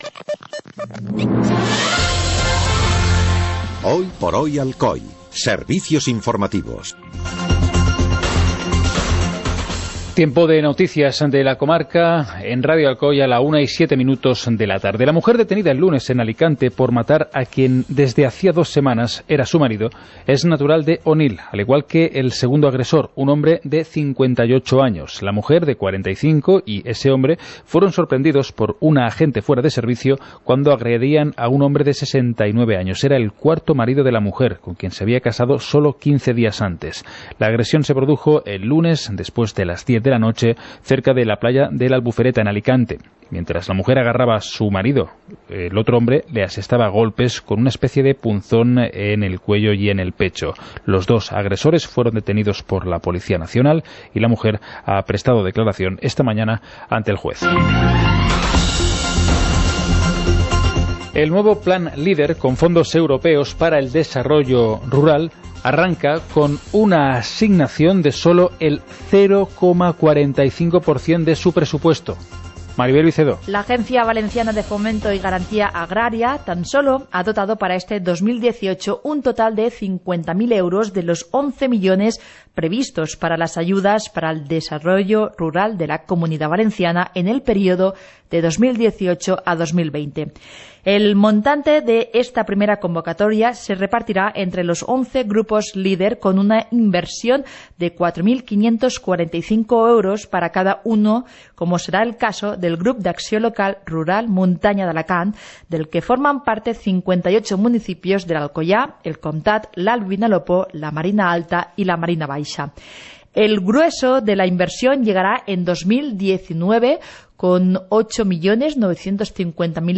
Informativo comarcal - jueves, 23 de agosto de 2018